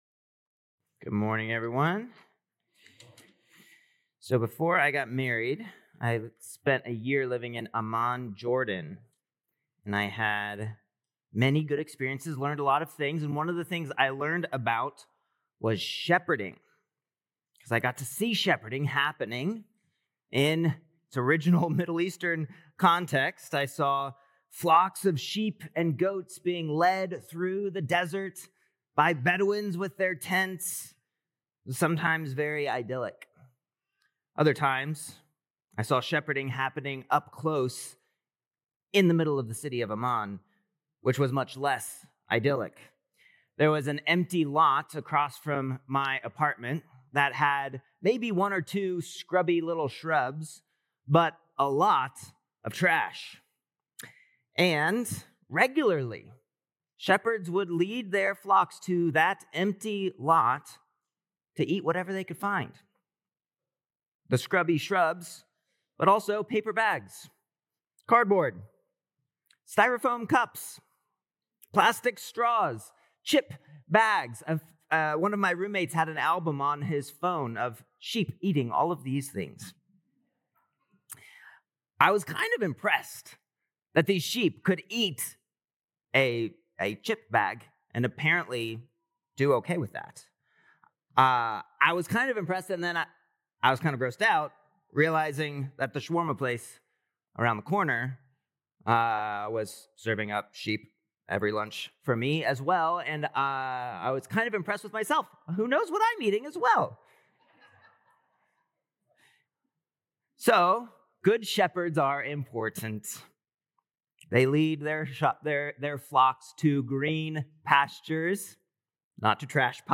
October 12th Sermon